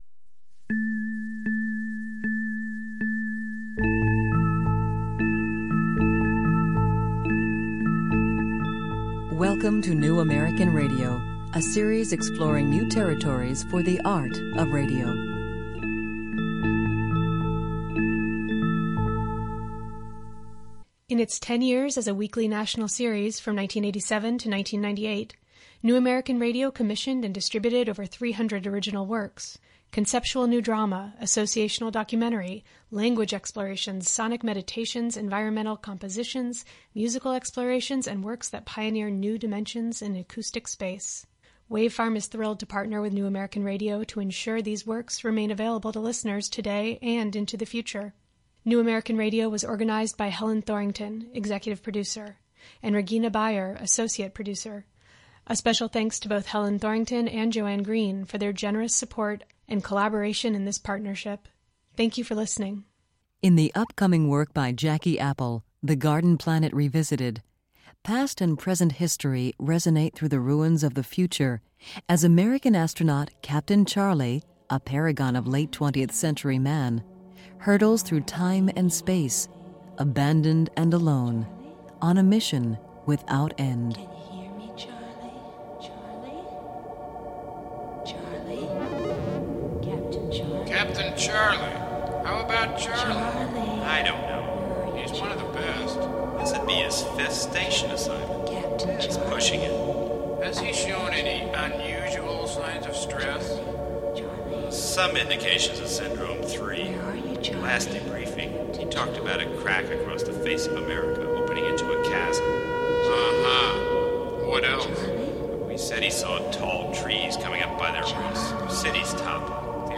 Originally presented in 1982 as a thirteen scene, hour-long multimedia performance written with music by trombonist